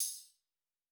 TC PERC 05.wav